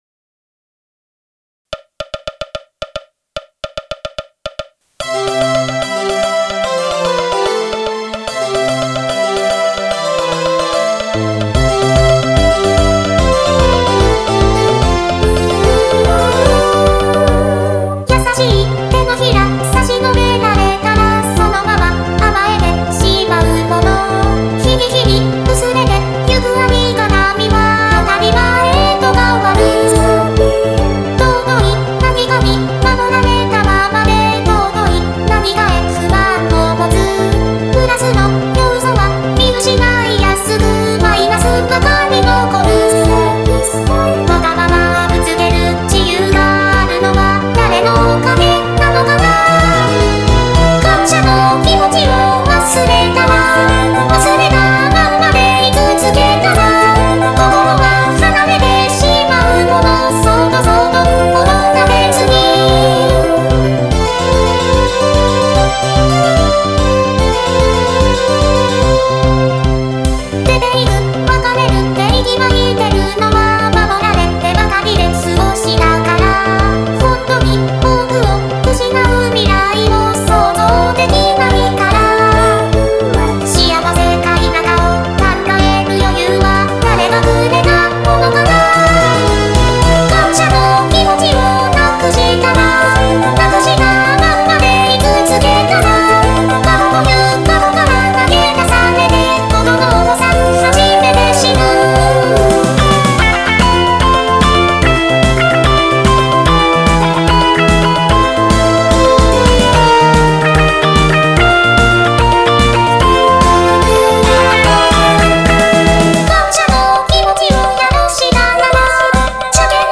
楽曲は、過去最短の長さ。明るい曲調です。